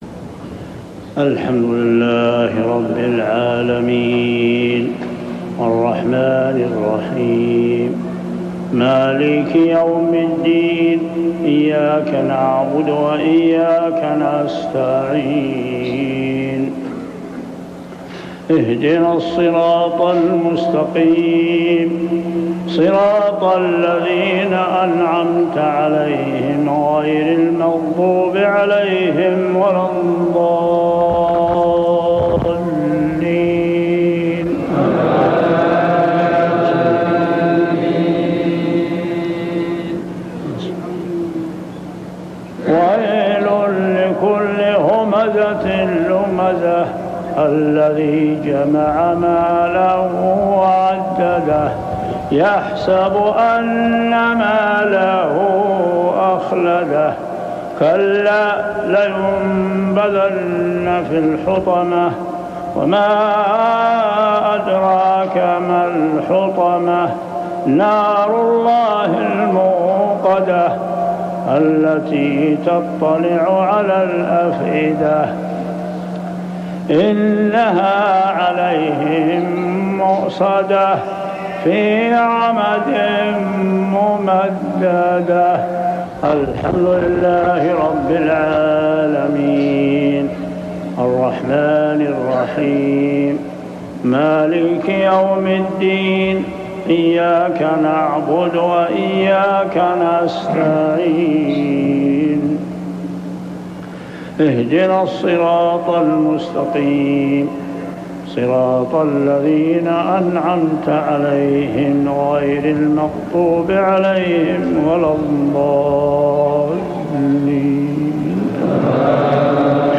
صلاة المغرب عام 1406هـ سورتي الهمزة و الفلق كاملة | Maghrib prayer Surah al-Humazah and al-Falaq > 1406 🕋 > الفروض - تلاوات الحرمين